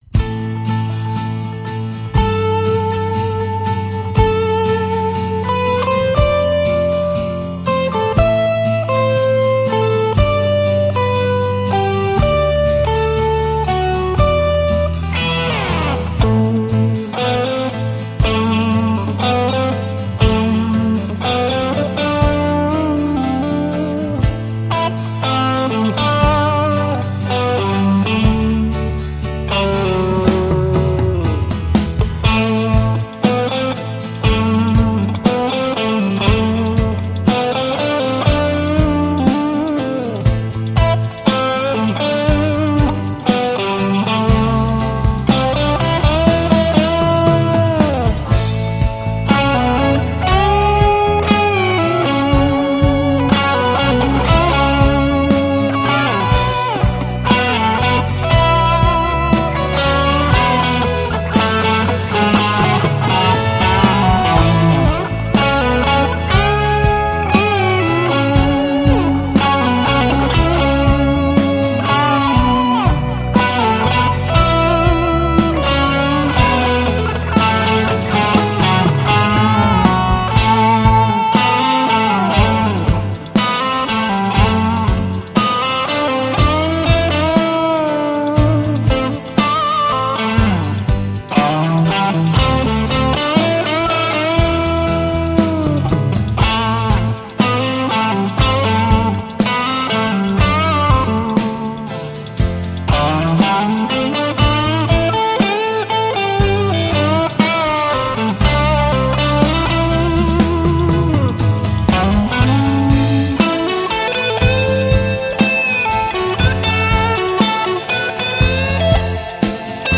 PISTES AUDIO (guitares & basse) + MIDI